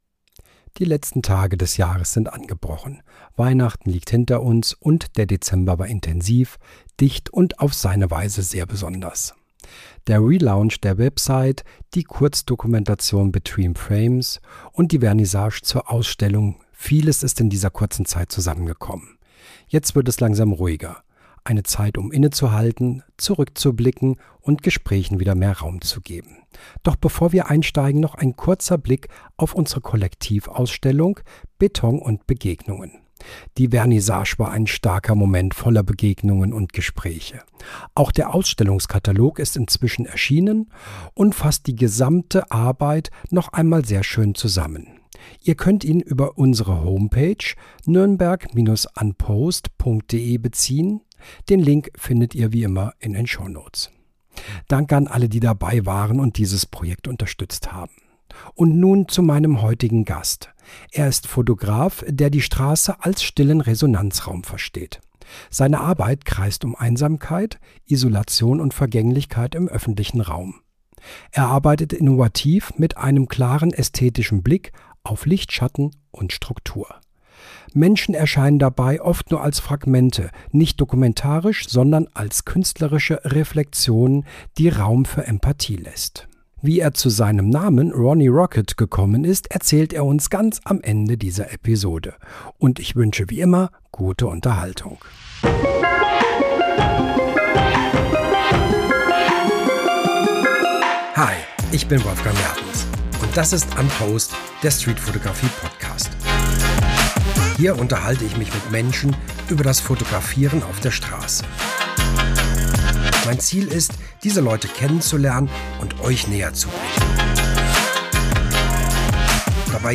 In dieser Episode spreche ich mit einem Streetfotografen über Einsamkeit, Isolation und Vergänglichkeit im öffentlichen Raum.
Ein Gespräch über serielle Arbeit, künstlerische Haltung und stille Beobachtung.